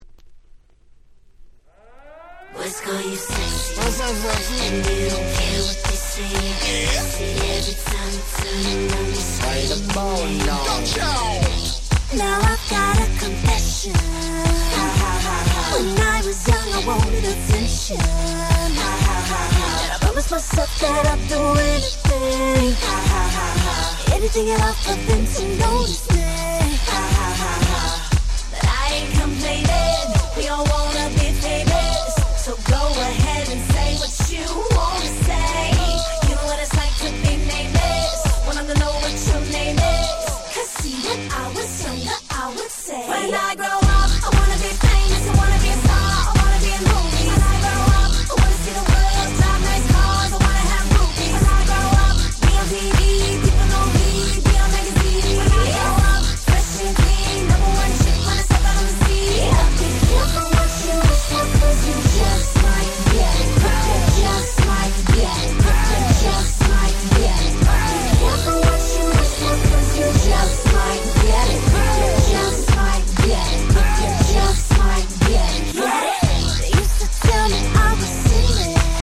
08' Super Hit R&B !!
時代を少し先取りしたかの様なアップテンポなダンスナンバー！！